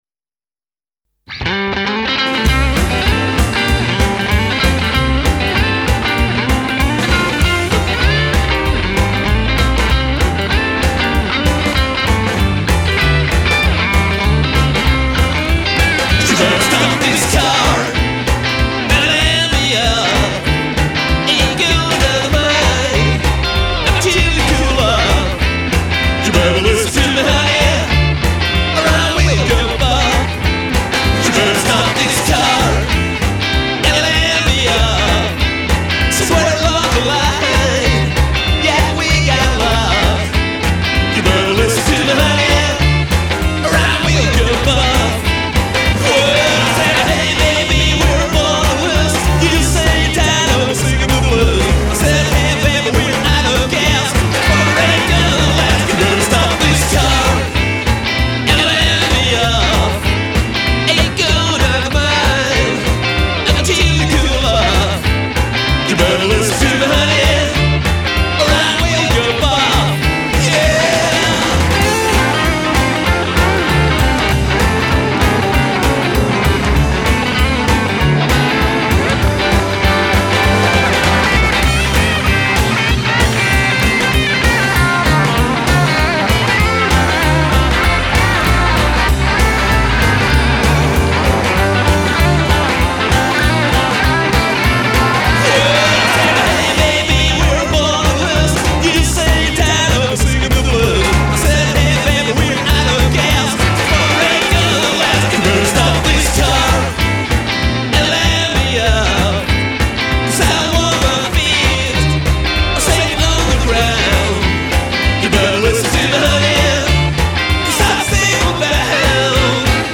Add some cool Country, Surf & Swing.